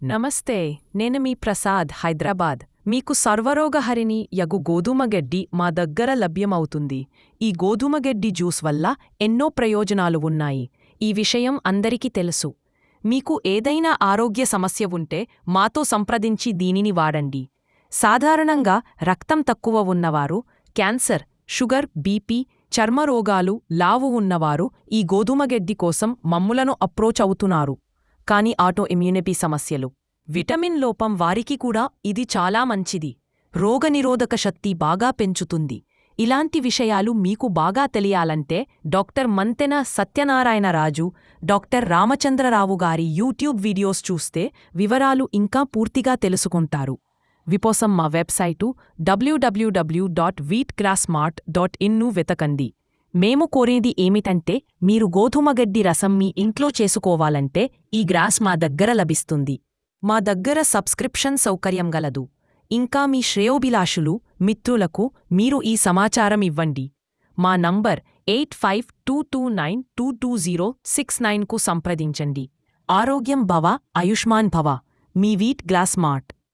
openai-fm-nova-friendly.mp3